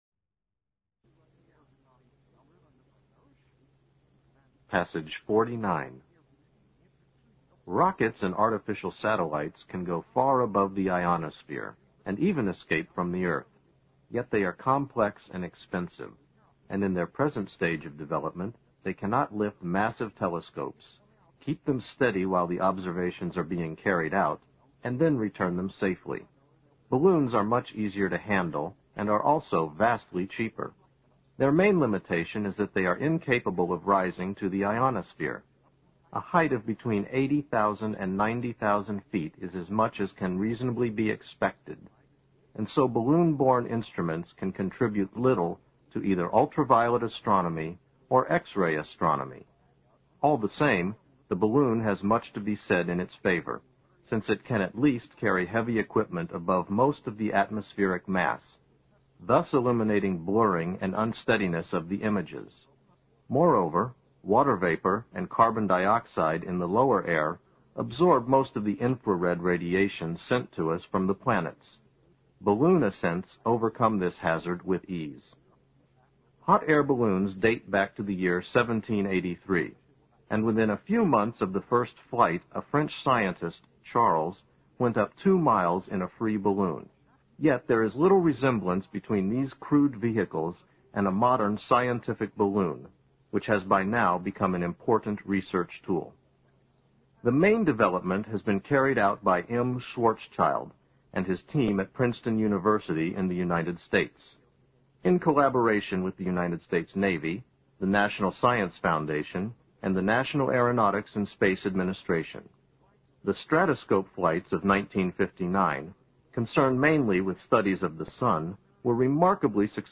新概念英语85年上外美音版第四册 第49课 听力文件下载—在线英语听力室